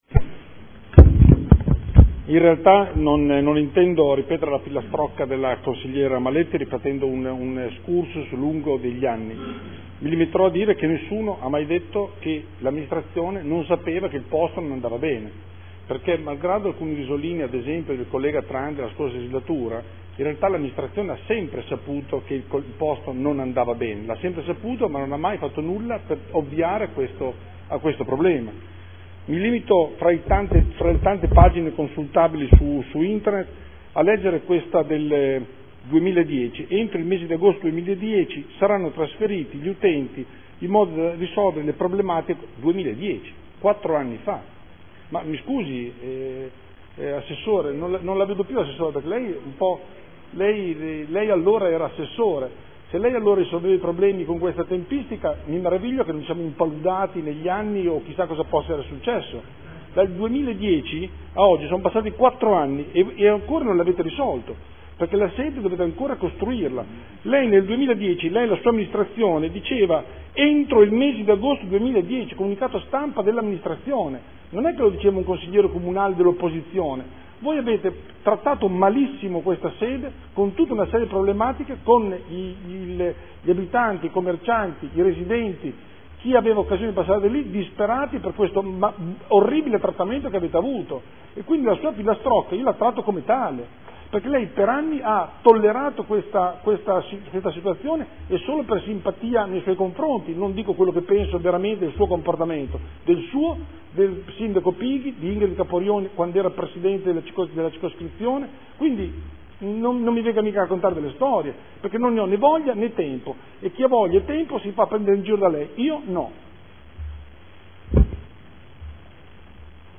Seduta del 3/11/2014. Dibattito su ordini del giorno